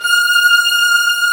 Index of /90_sSampleCDs/Roland LCDP13 String Sections/STR_Violins I/STR_Vls4 6pc
STR VIOLIN09.wav